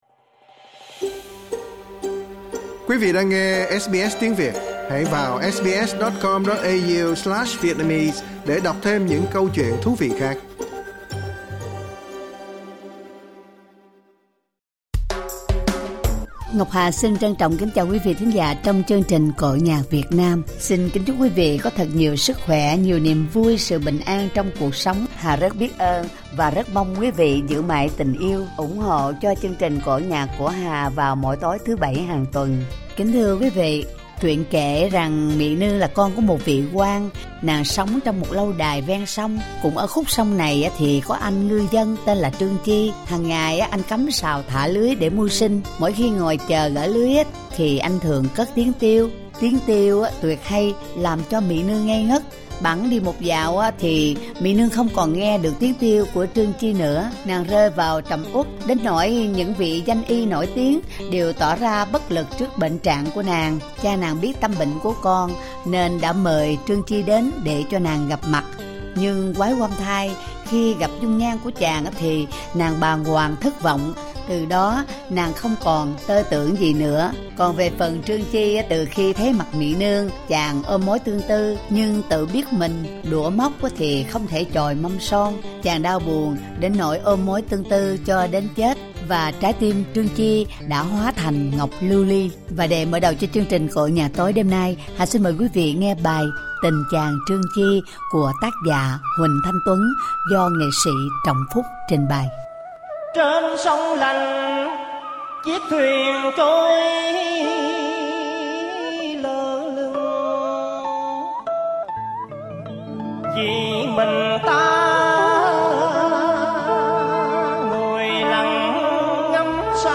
ca cảnh